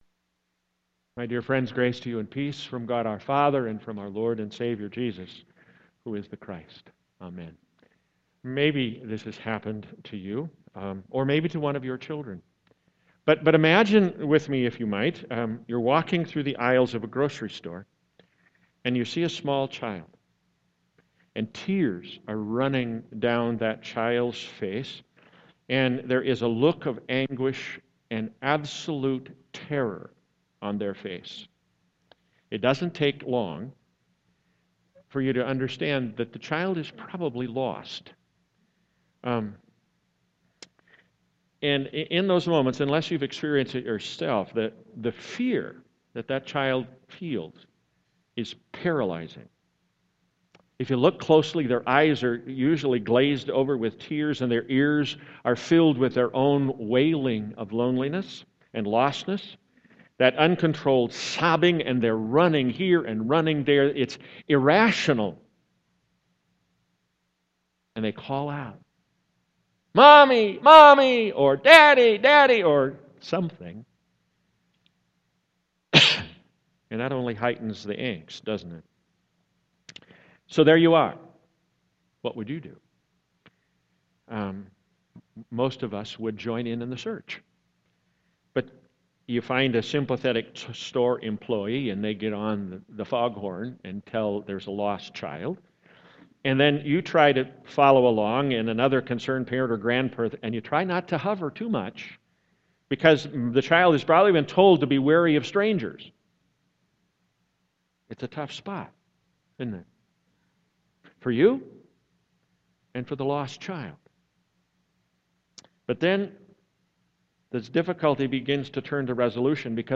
Sermon 7.19.2015